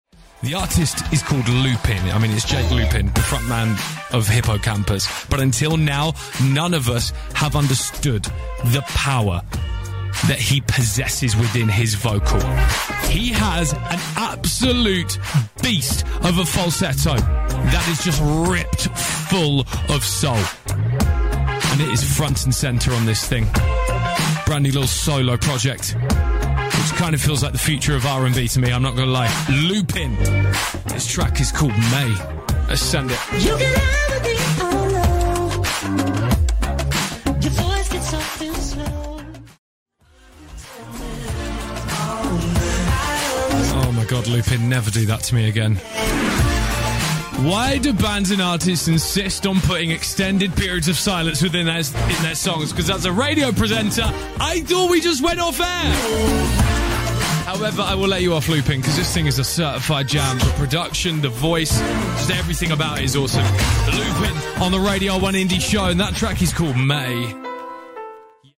ドリームポップ！